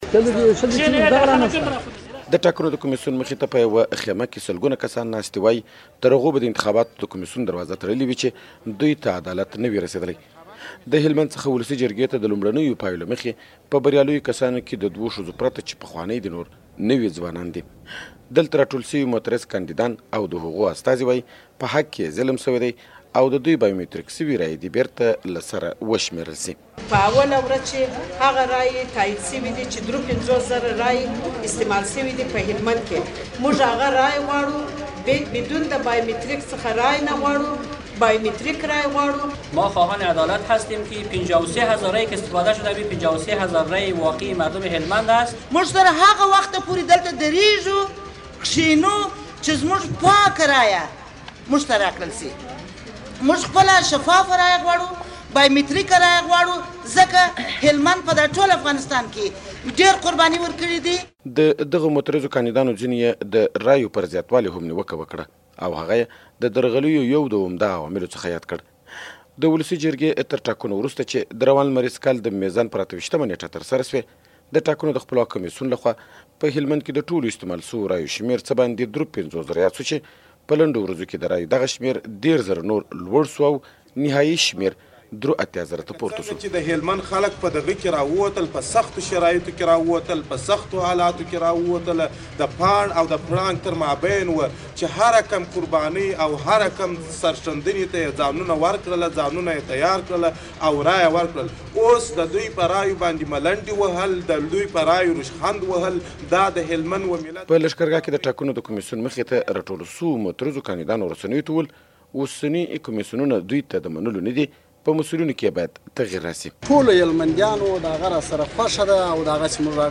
د هلمند راپور